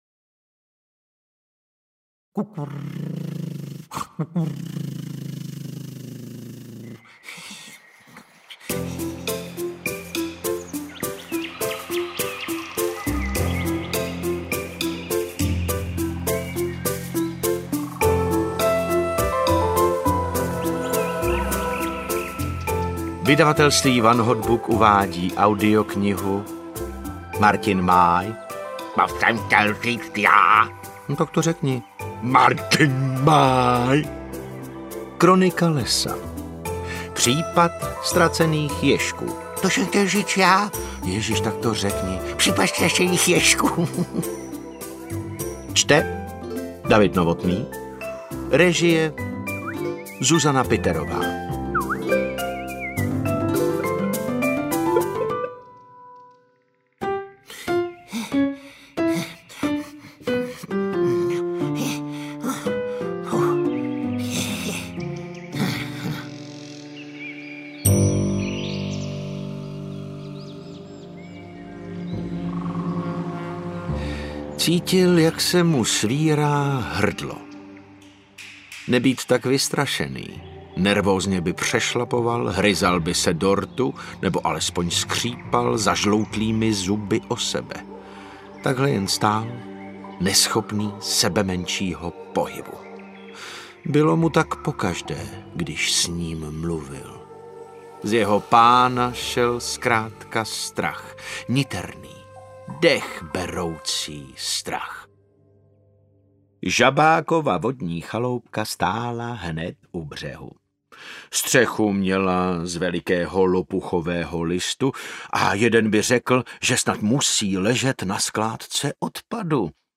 Audio knihaKronika lesa 1: Případ ztracených ježků
Ukázka z knihy
• InterpretDavid Novotný
kronika-lesa-1-pripad-ztracenych-jezku-audiokniha